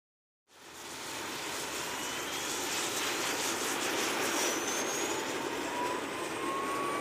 starter_air.mp3